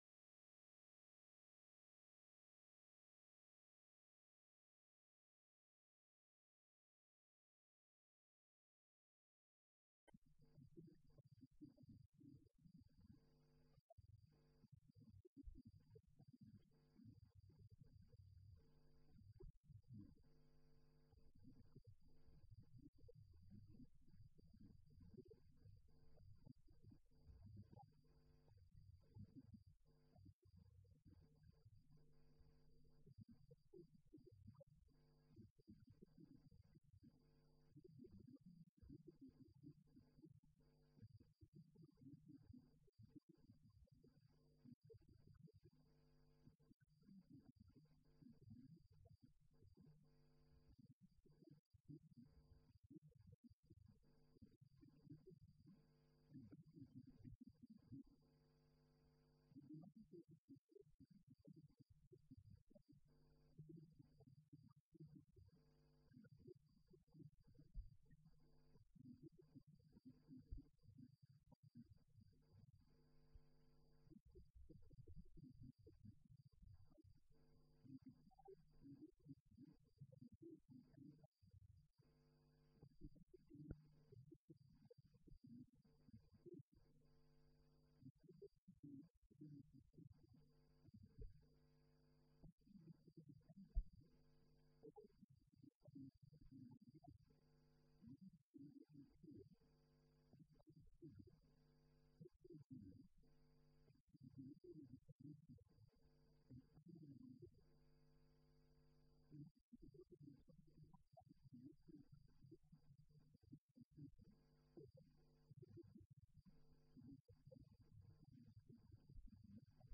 Colloque L’Islam et l’Occident à l’époque médiévale.